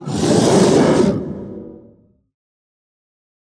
KS_Ogre_2.wav